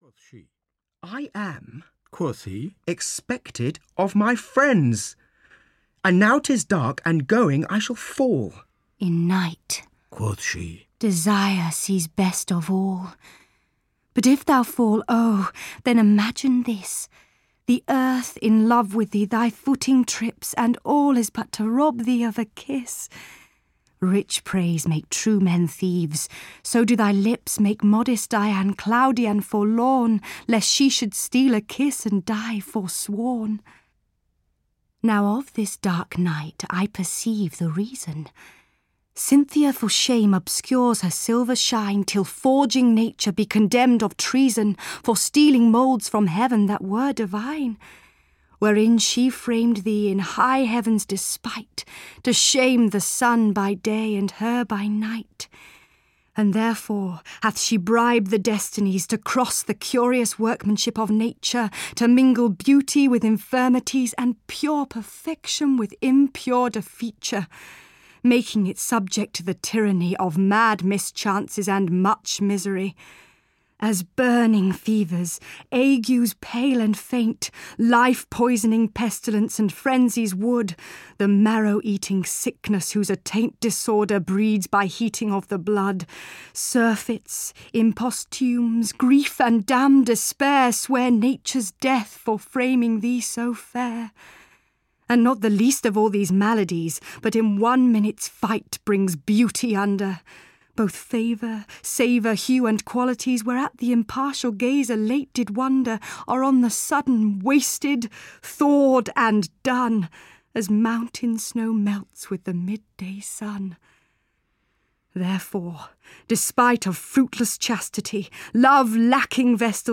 Venus & Adonis, The Rape of Lucrece (EN) audiokniha
Ukázka z knihy